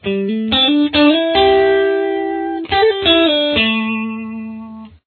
Below are some examples of licks to play that cross over from pattern to pattern using primarily the pentatonic minor lead pattern.